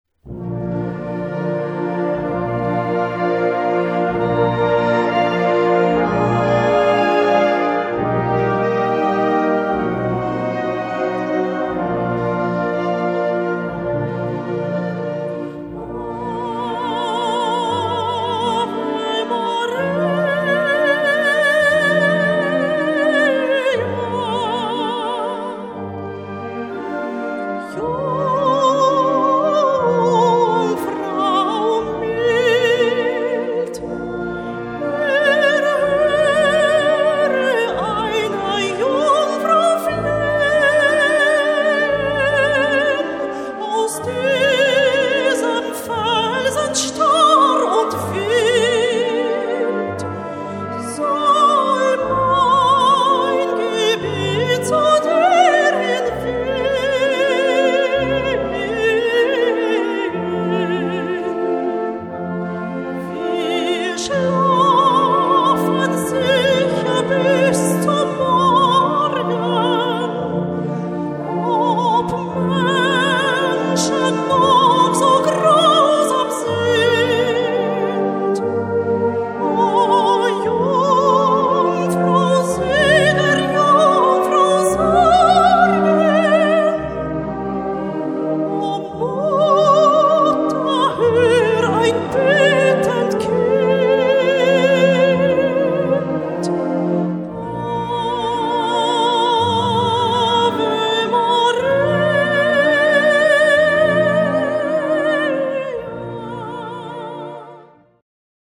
Solo für Trompete oder Gesang
Besetzung: Blasorchester